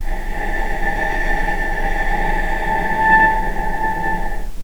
vc-A5-pp.AIF